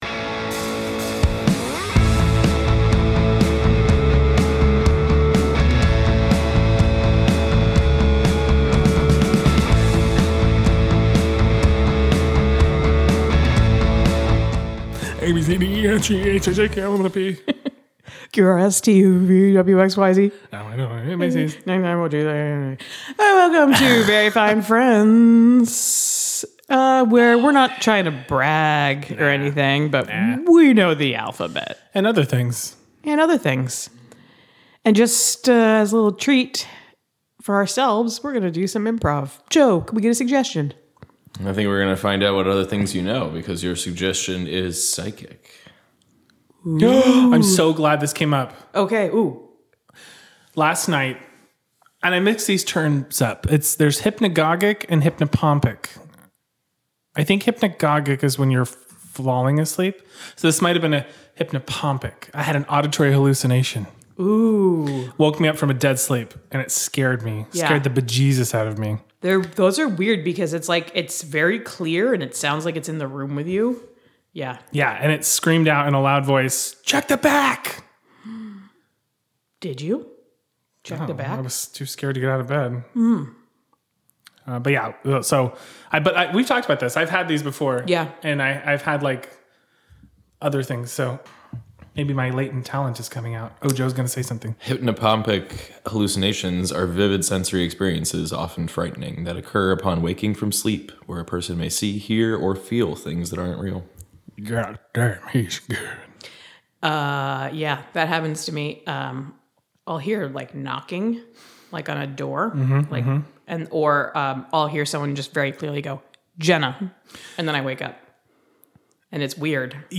Bonus IMPROV - I Thought You'd Have Access to Other Mental Faculties